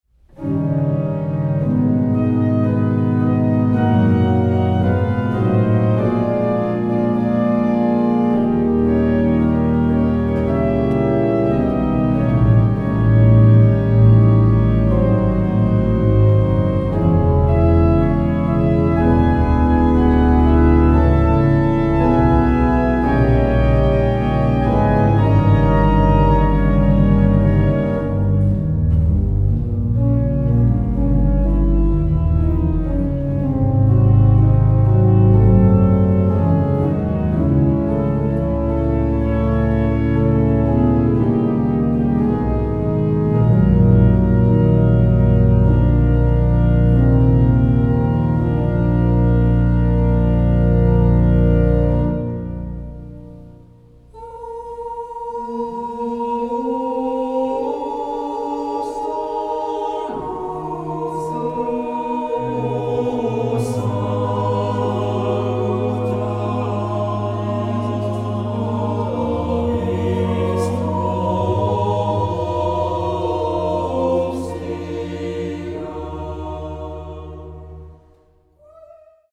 Genre-Style-Forme : Sacré ; Motet ; contemporain
Type de choeur : SATB  (4 voix mixtes )
Tonalité : mi mineur